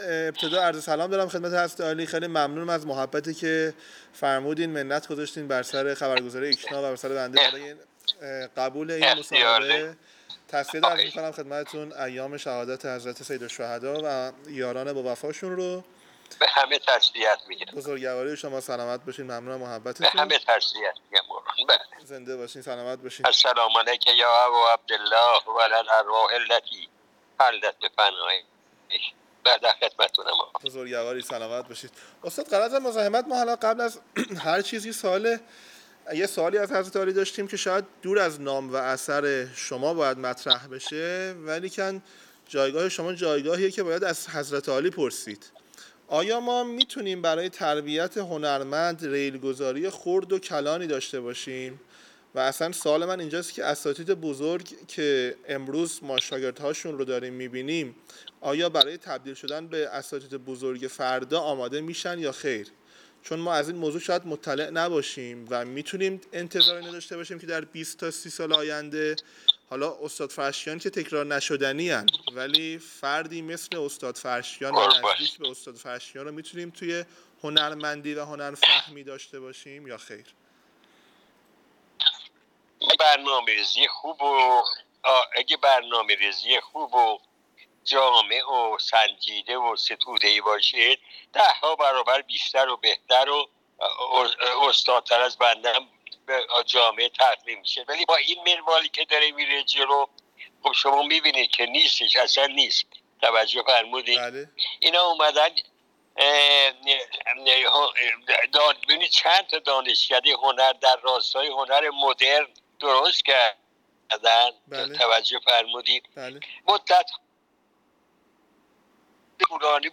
گفت‌وگوی اختصاصی ایکنا با استاد محمود فرشچیان؛